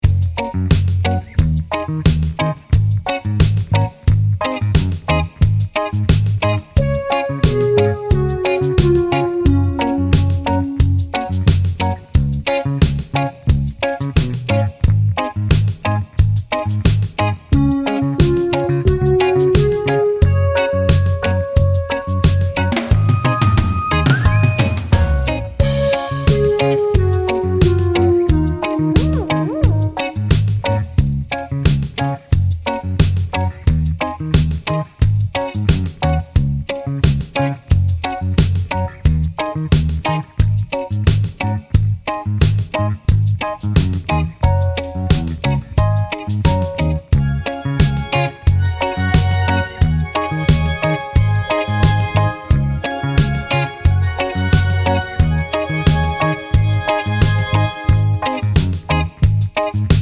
Album Version with No Backing Vocals Reggae 5:30 Buy £1.50